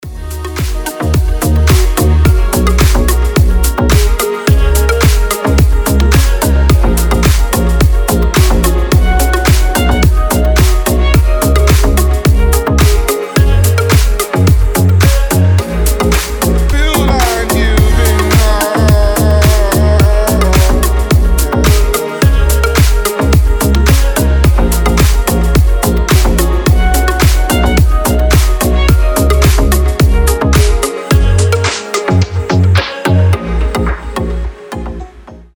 • Качество: 320, Stereo
мужской голос
deep house
мелодичные
nu disco
Приятная расслабляющая музыка